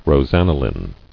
[ros·an·i·line]